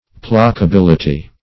Search Result for " placability" : The Collaborative International Dictionary of English v.0.48: Placability \Pla`ca*bil"i*ty\, n. [L. placabilitas: cf. F. placabilit['e].] The quality or state of being placable or appeasable; placable disposition.